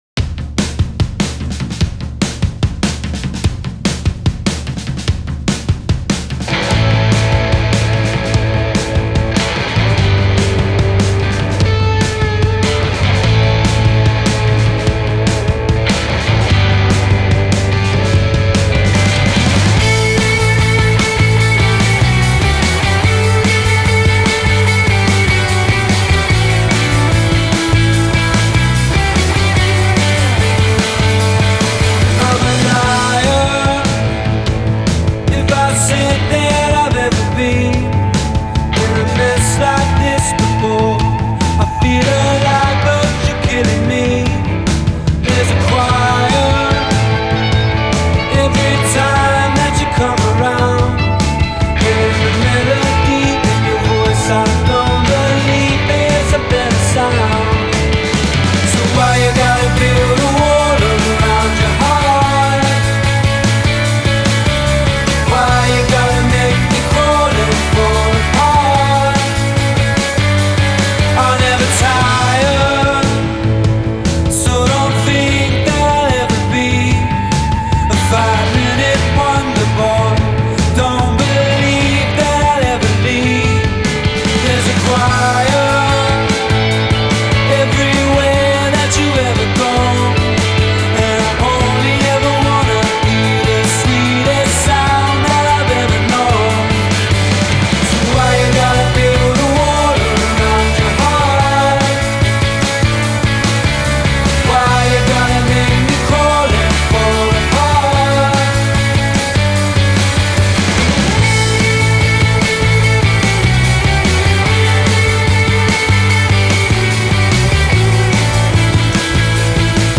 Poprock is primarily a guitar-based genre.